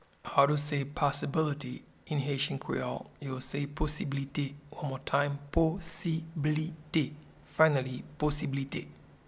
Pronunciation and Transcript:
Possibility-in-Haitian-Creole-Posiblite.mp3